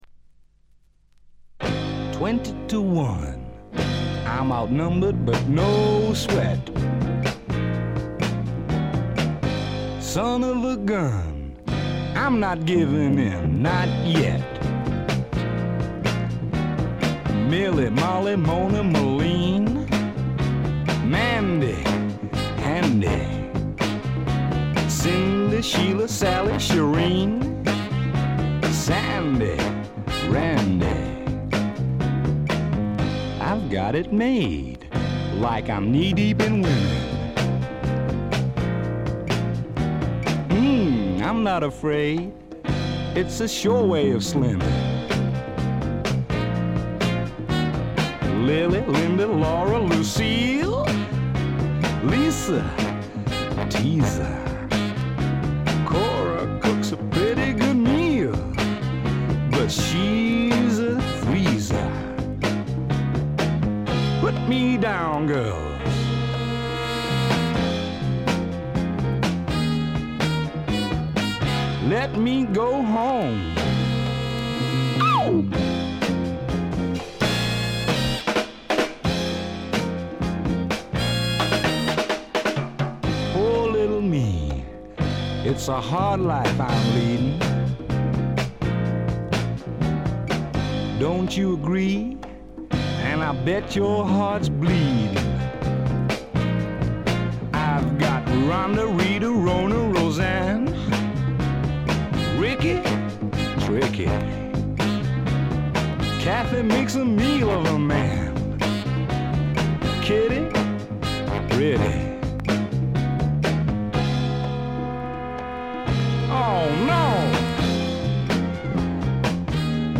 わずかなチリプチ。散発的なプツ音が数回。
試聴曲は現品からの取り込み音源です。